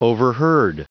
Prononciation du mot overheard en anglais (fichier audio)
Prononciation du mot : overheard